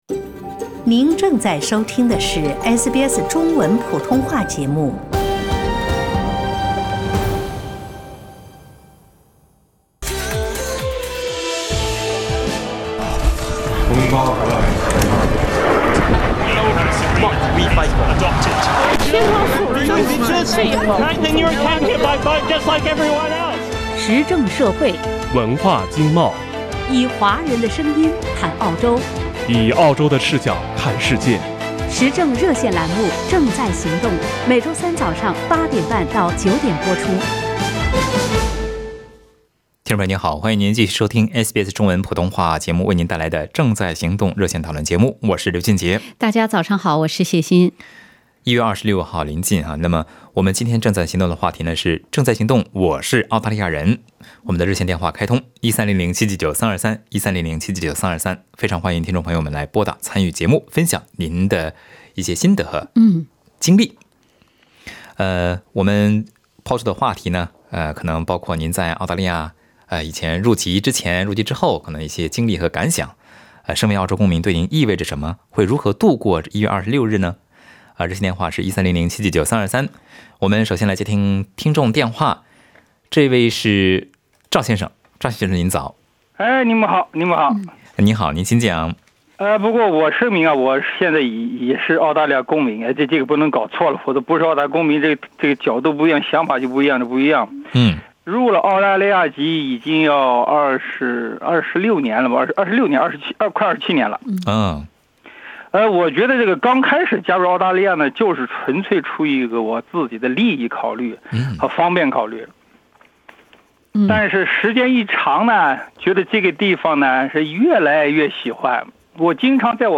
本期《正在行动 – 我是澳大利亚人》热线节目中，听众朋友们分享了自己的理解和经历。